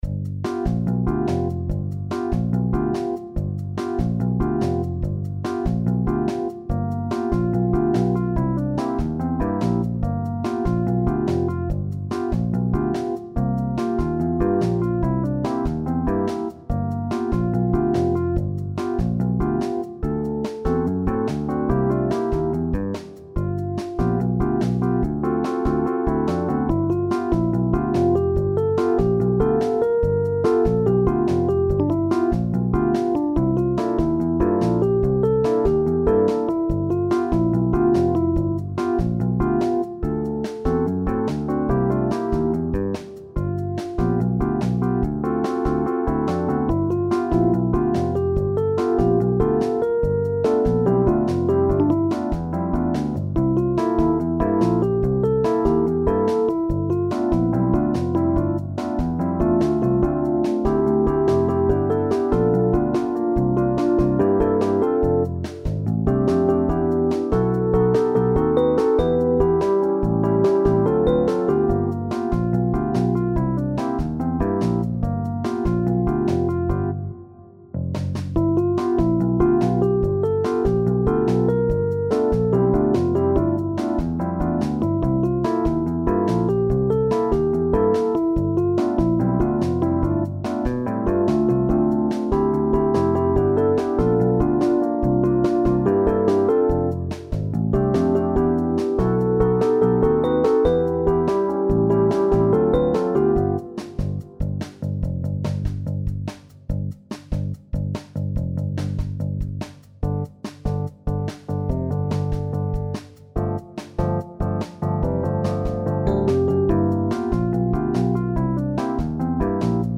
SSATB with solo
An arrangement in a 1960s Latin style